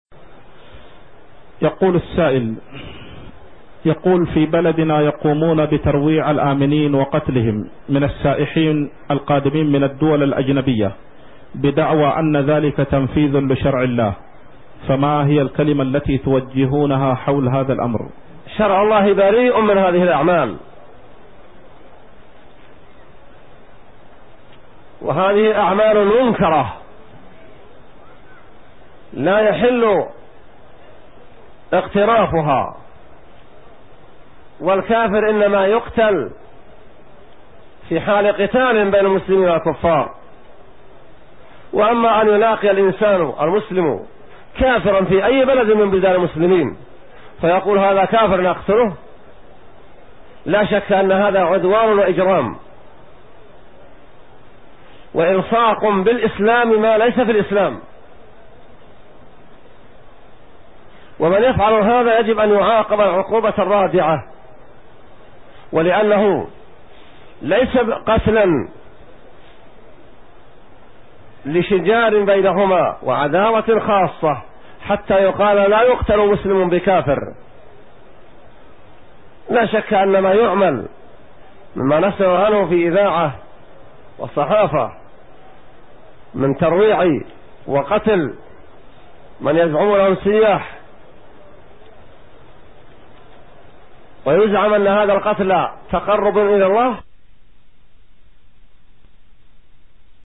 Format: MP3 Mono 22kHz 32Kbps (CBR)